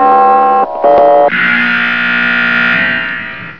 infernal buzzer gong doohickey went off sending them on their next mission.